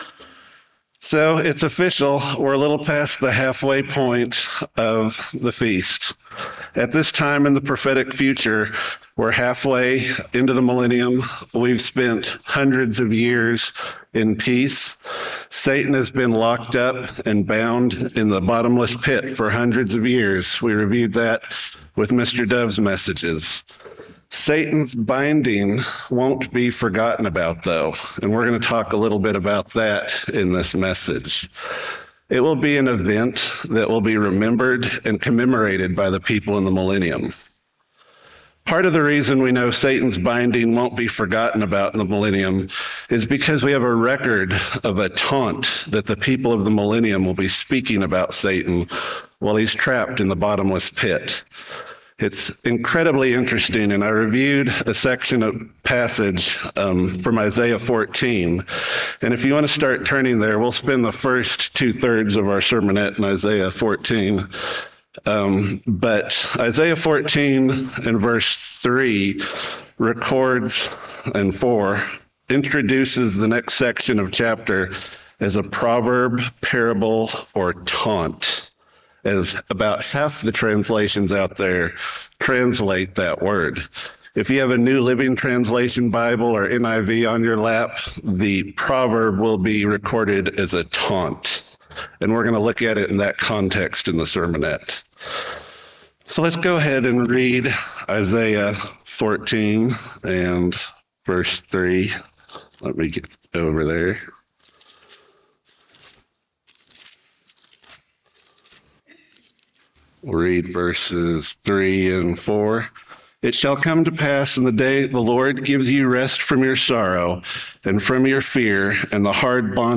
Sermons
Given in Aransas Pass, Texas